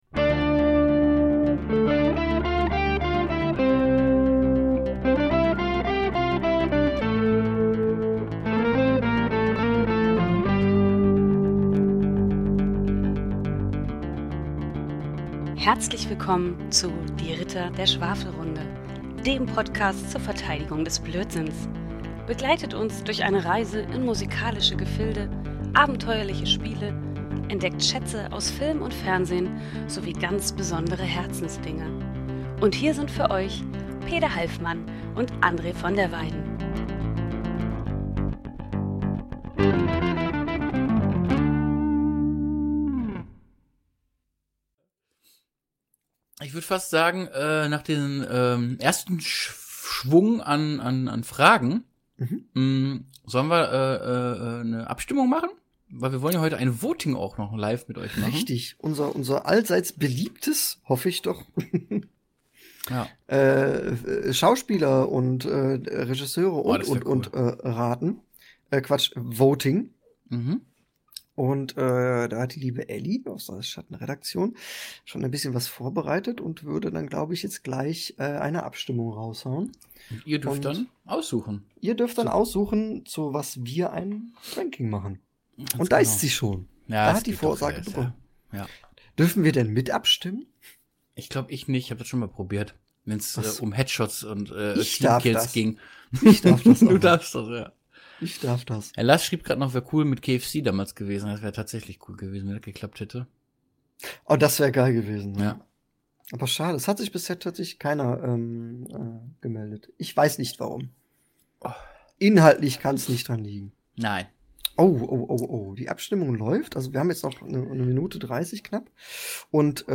Heute servieren wir euch Teil 2 unseres großen Live-Schwafelmarathons – frisch aus der Unterhaltungsbox der guten Laune™.
Lachflashs, wildes Klicken, Stille, Verwirrung – alles dabei.